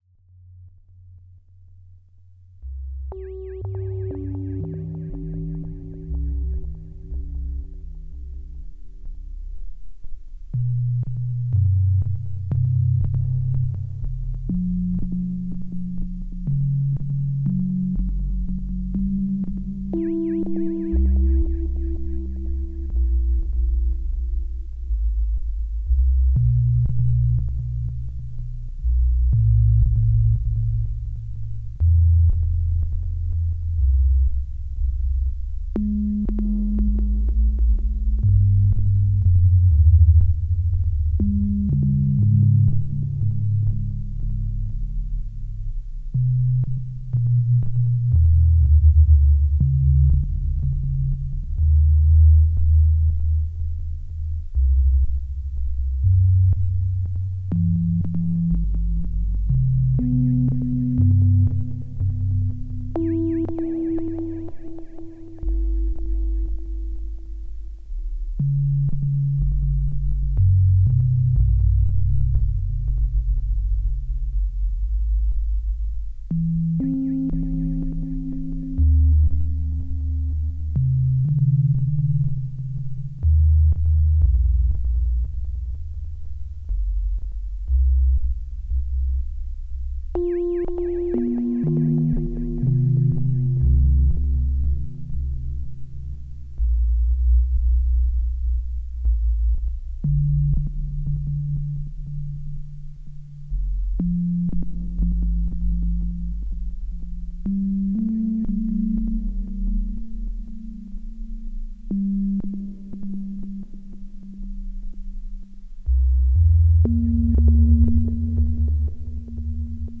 Réalisé à partir d'un son du 2600. Ajout du Model-D au ruban à partir de 4'55 (env.).
Paix, douceur, tendresse, calme...